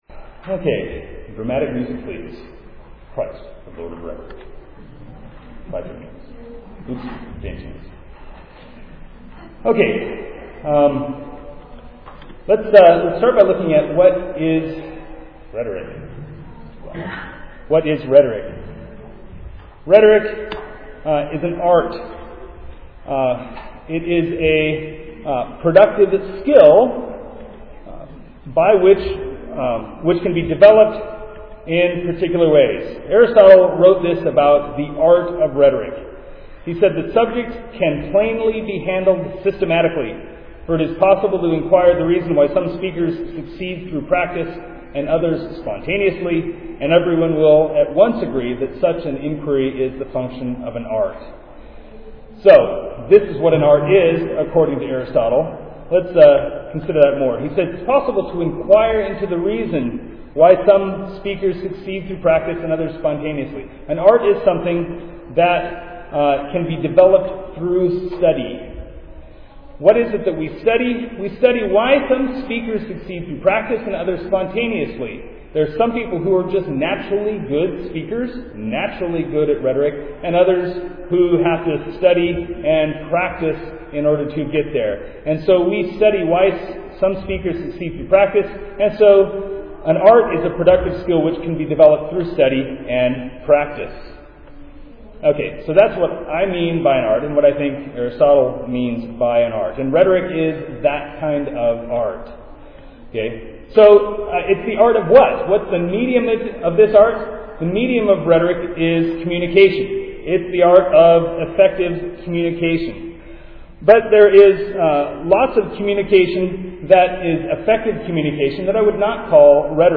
2007 Workshop Talk | 0:47:46 | 7-12, Virtue, Character, Discipline
The Association of Classical & Christian Schools presents Repairing the Ruins, the ACCS annual conference, copyright ACCS.